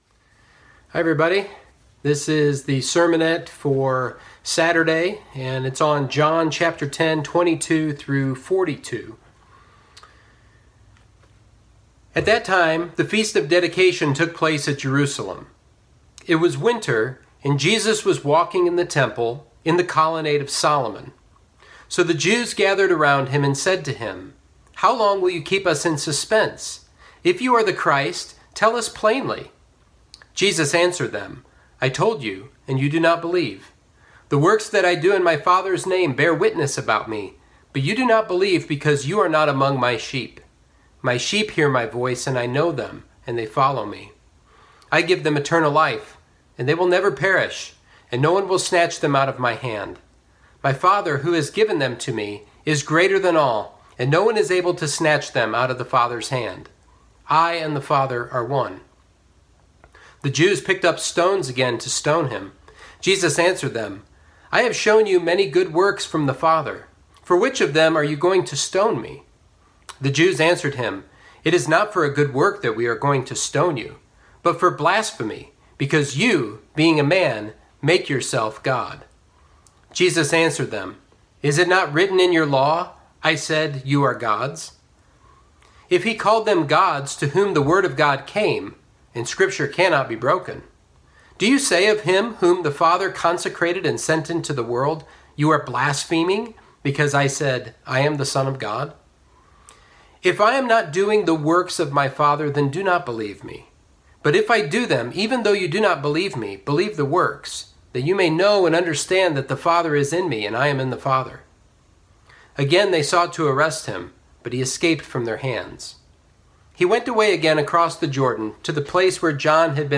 Sermonette 3/28: John 10:22-42